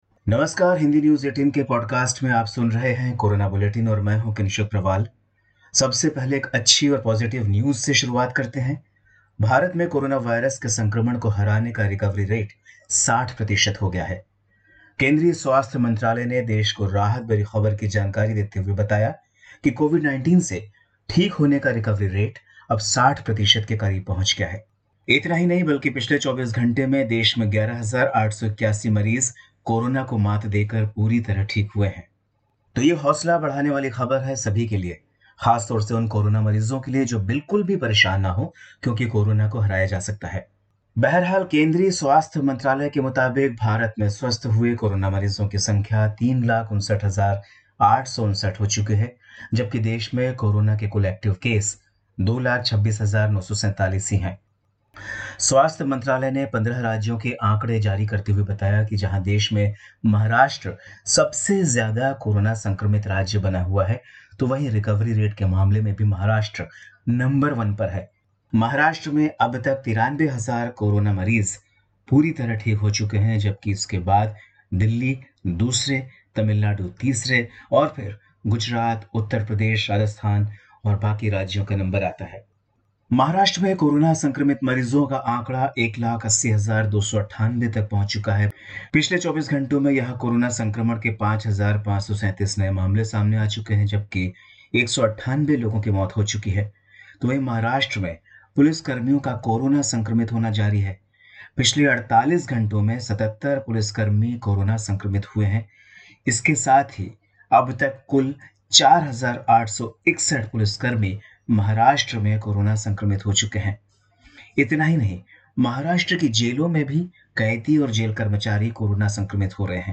देश-दुनिया की अभी तक की कोरोना वायरस से जुड़ी बड़ी खबरें जानने के लिए सुनते रहिए कोरोना बुलेटिन